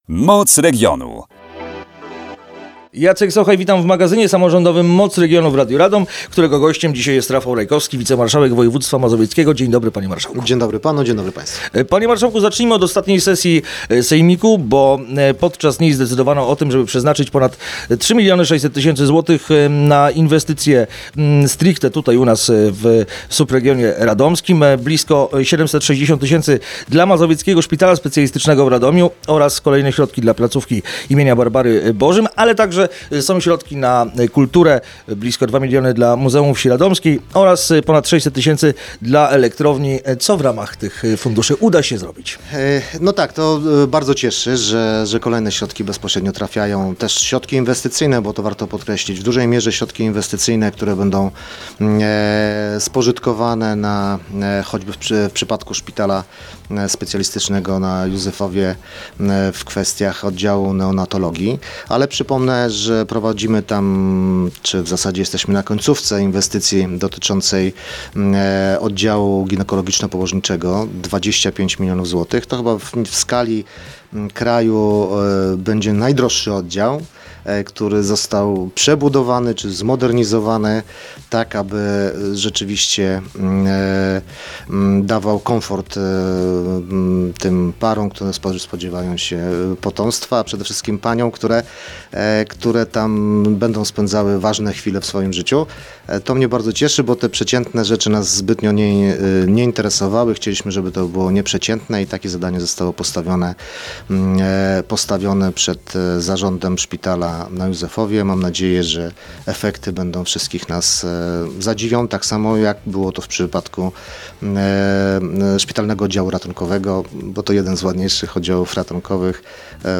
Rafał Rajkowski, wicemarszałek województwa mazowieckiego